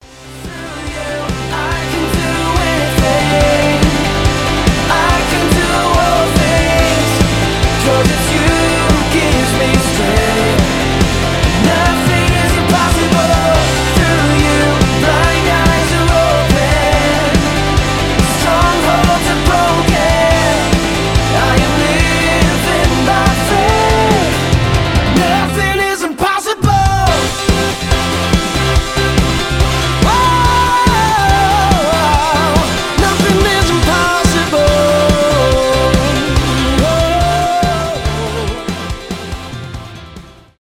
христианский рок